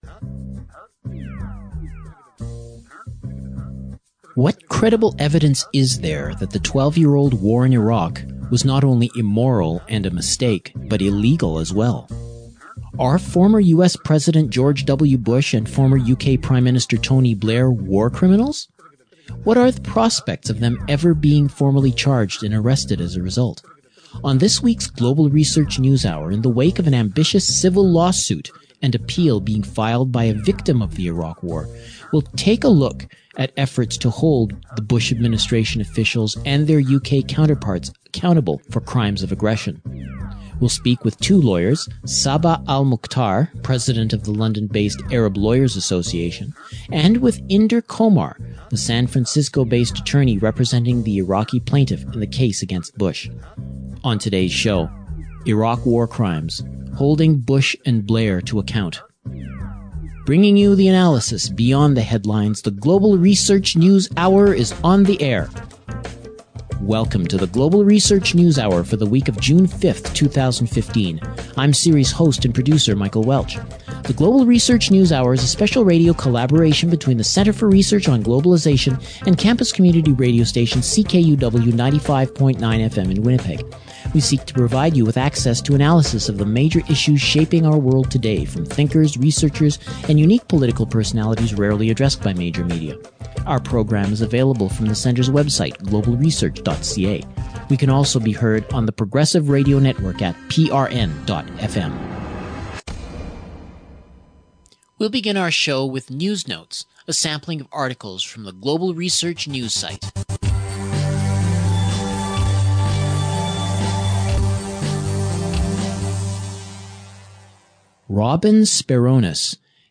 Two Lawyers discuss the prospects of the two leaders actually being charged with war crimes.